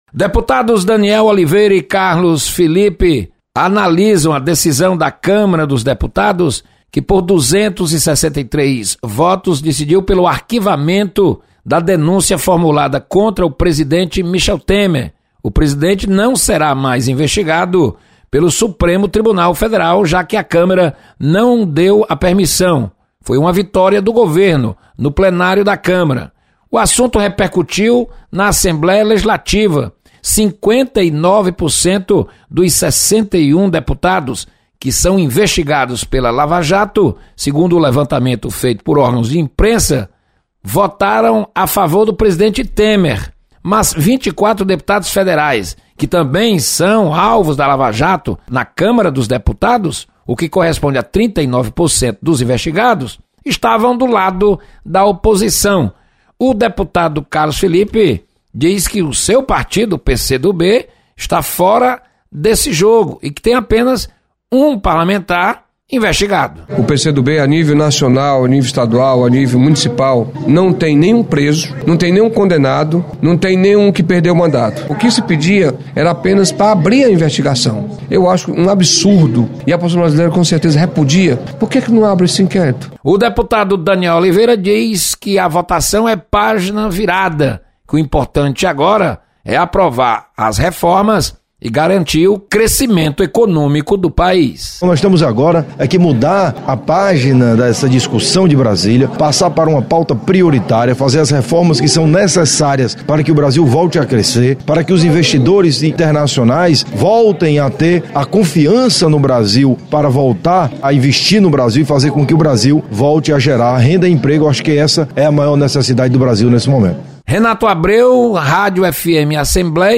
Parlamentares avaliam o arquivamento de denúncia contra o Presidente Michel Temer. Repórter